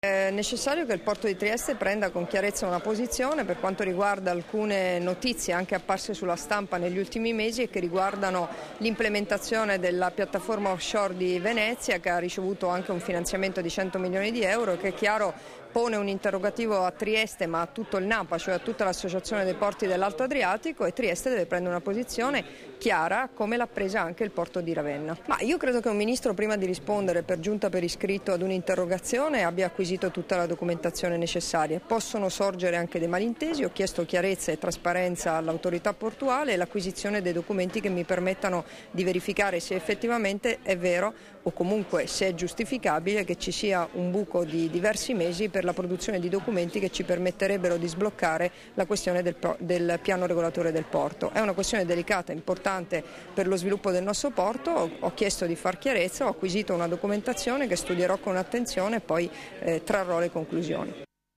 Dichiarazioni di Debrora Serracchiani (Formato MP3) rilasciate a margine della riunione del Comitato portuale sulla situazione del Piano regolatore del Porto di Trieste, a Trieste il 26 luglio 2013 [983KB]